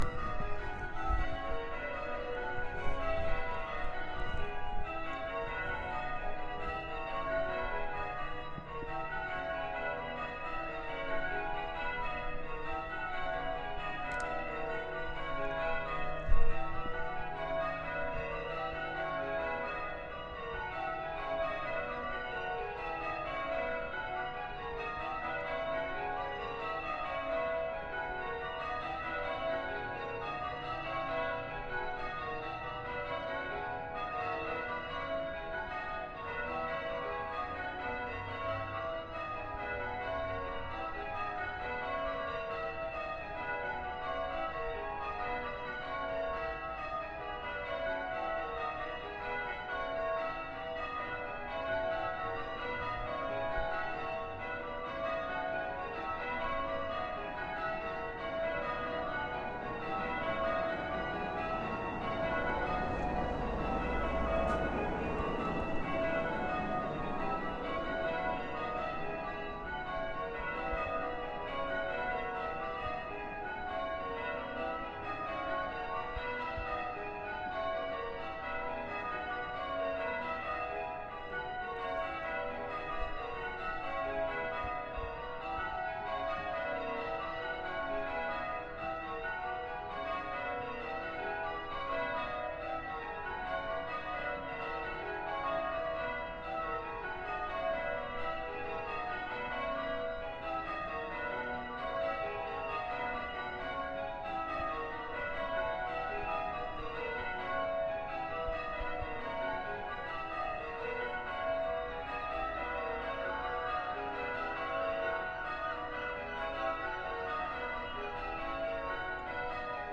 Bells of Old St Martin's Church